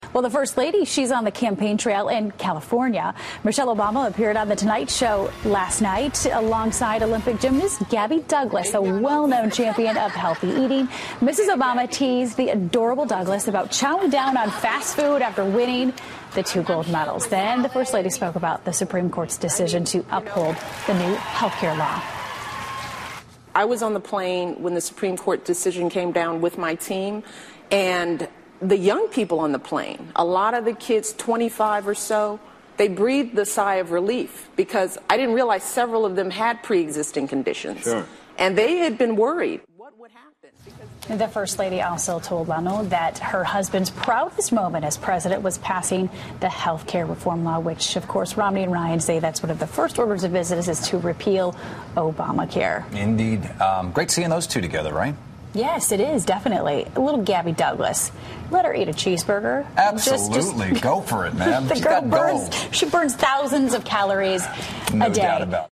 访谈录 2012-08-16&08-18 米歇尔·奥巴马专访 听力文件下载—在线英语听力室